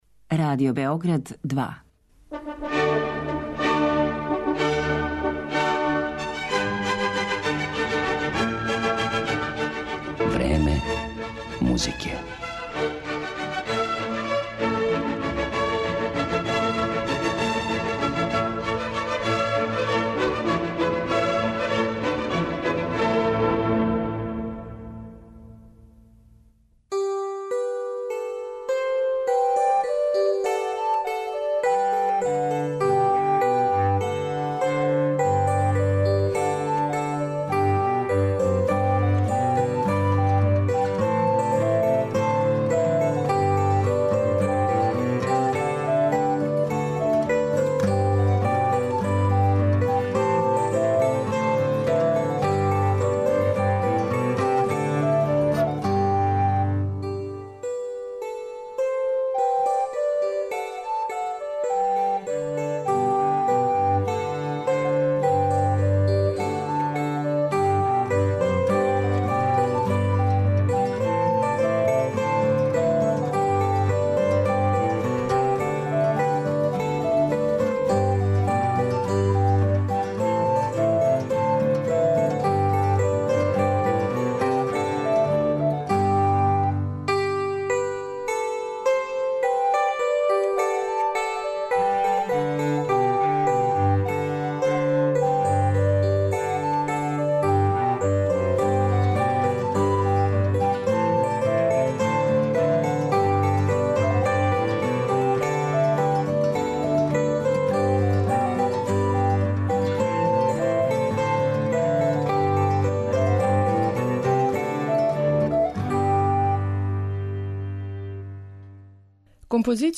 виолинисткиња
свирач кантелеа